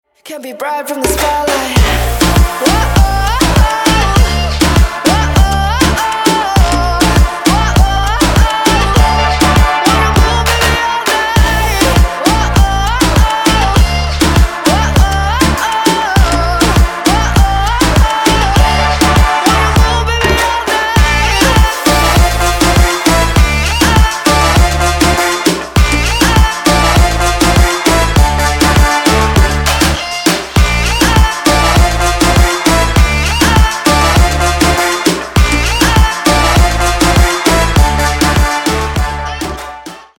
женский голос
dance
Electronic
EDM
club